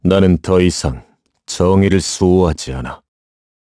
Clause_ice-vox-select_kr.wav